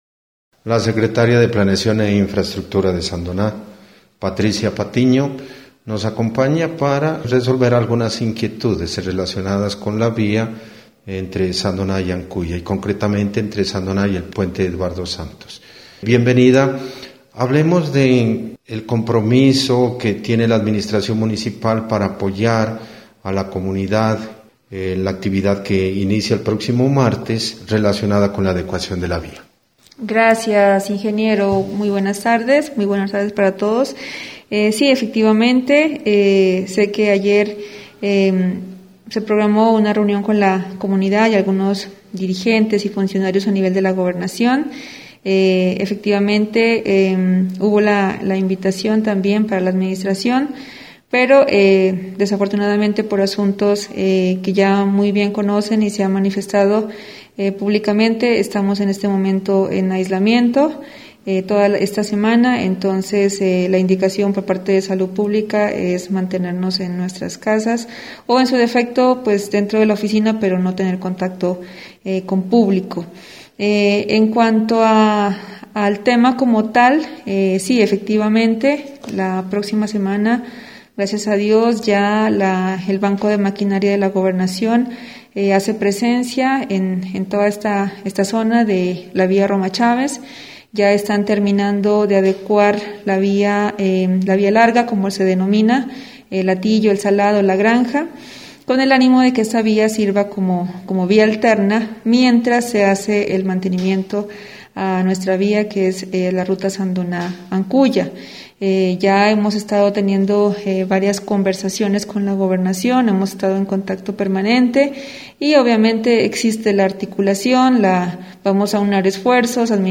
Entrevista con la secretaria de planeación e infraestructura Patricia Patiño.